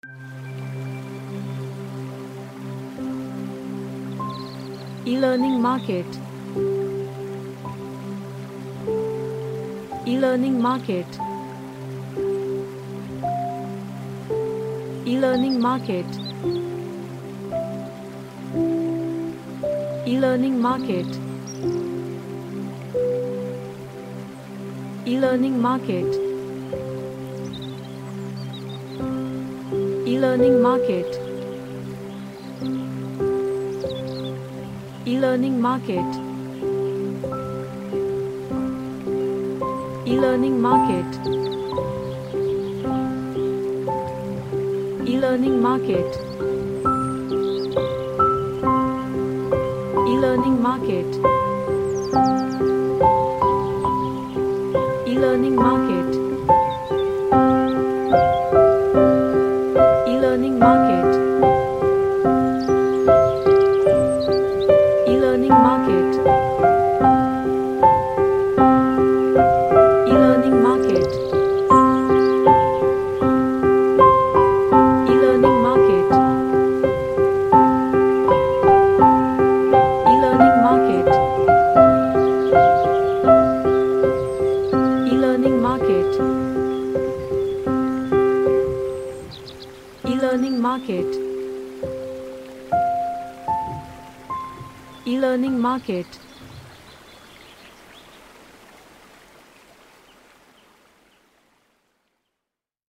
An ambient piano track
Relaxation / MeditationAmbient